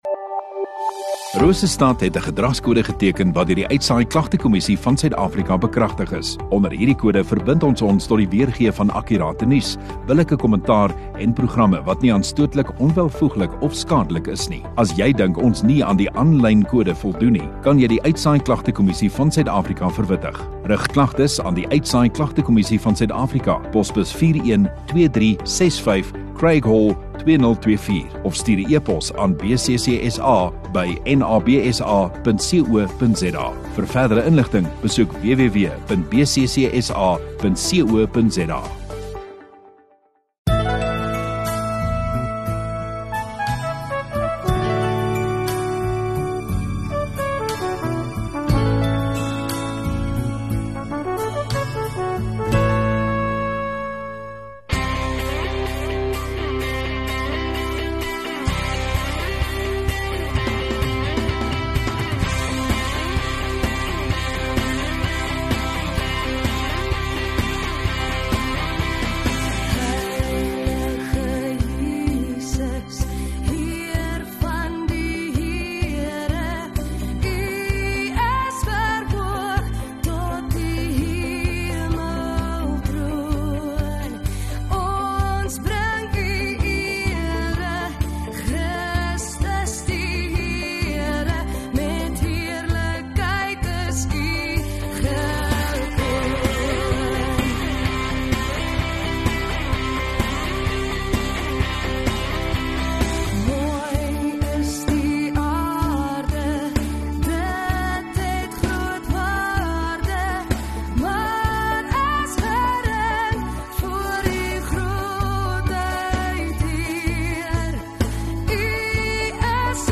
4 Nov Saterdag Oggenddiens